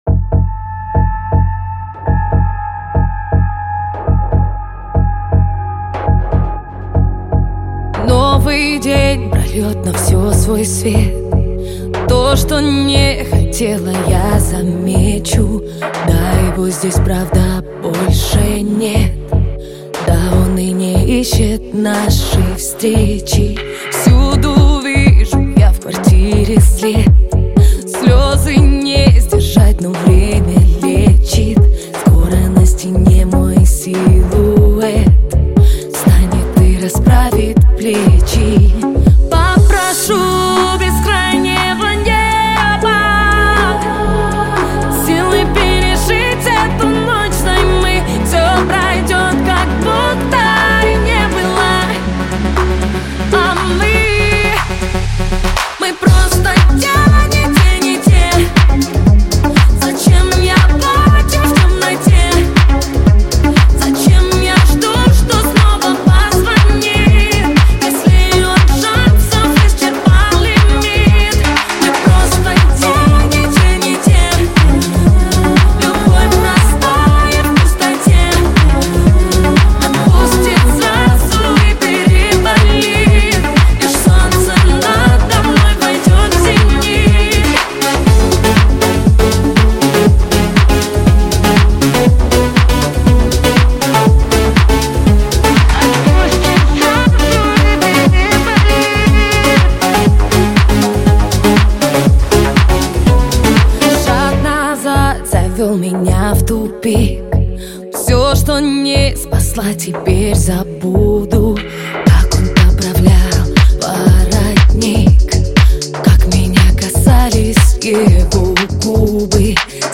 • Жанр песни: Жанры / Поп-музыка